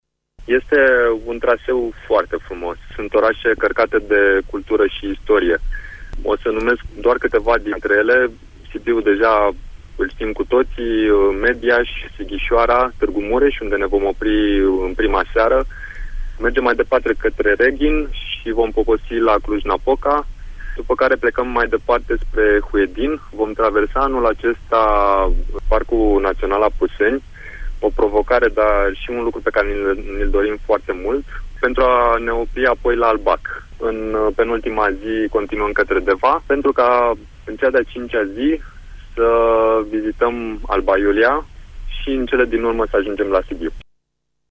stiri-2-sept-ciclaton-traseu.mp3